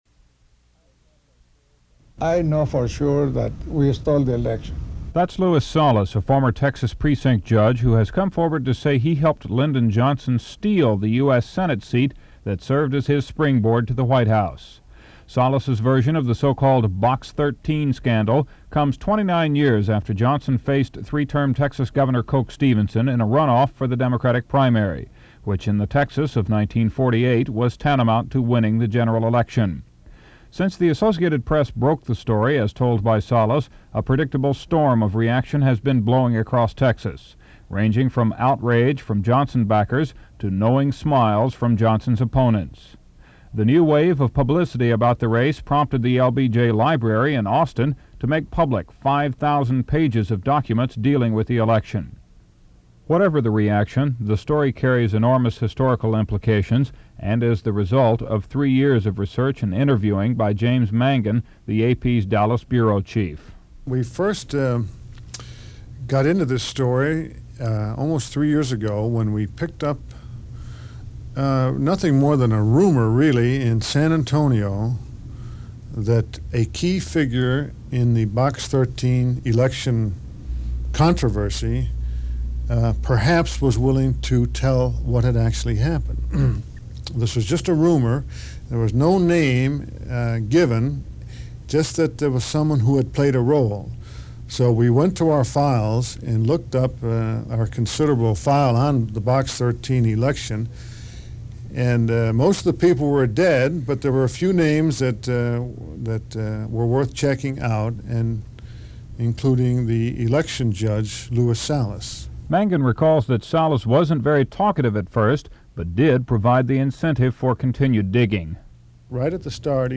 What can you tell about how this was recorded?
Format Audio tape